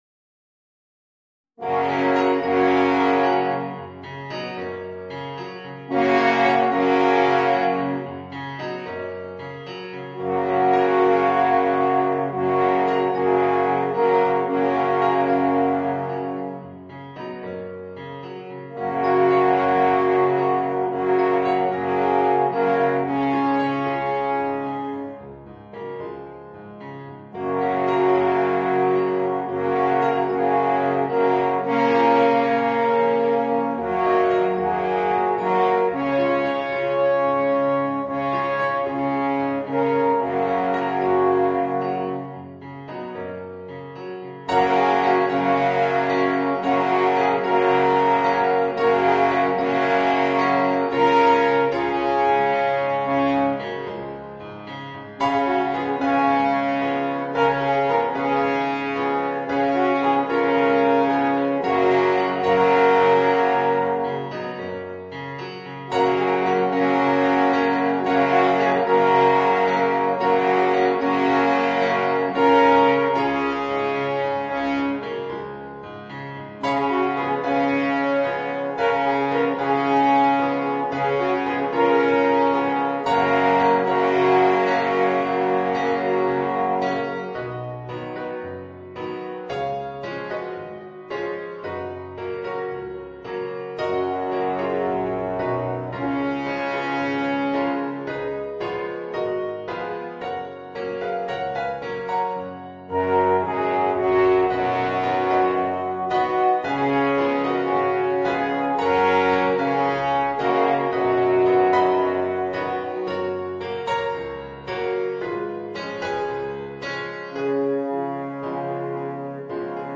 Voicing: 3 Alphorns and Piano